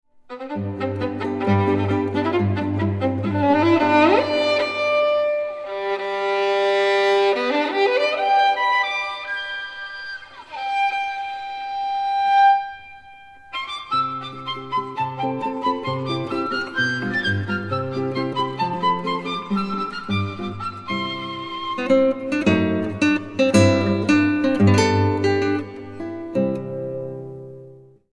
Genre: Classical
violin
guitar
Recorded November 1992, Berlin, Jesus-Christus-Kirche.